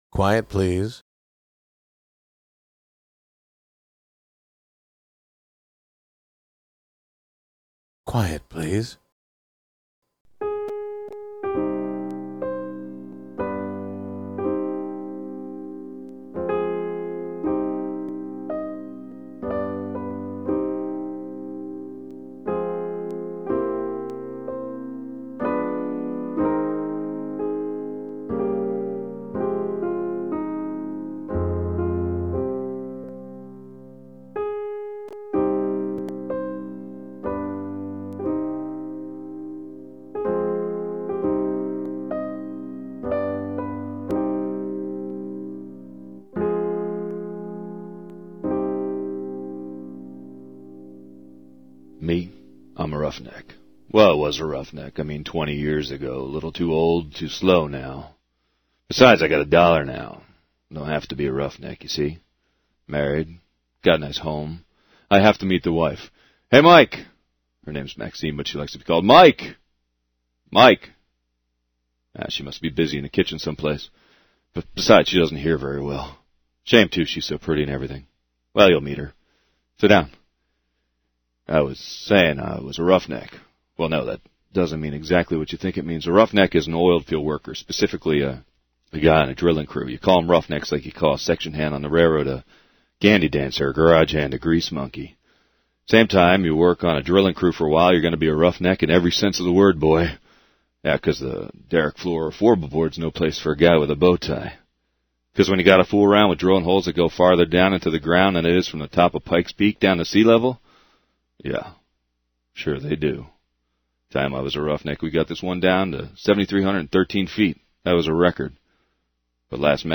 from the Golden Age of Radio:
from a live appearance on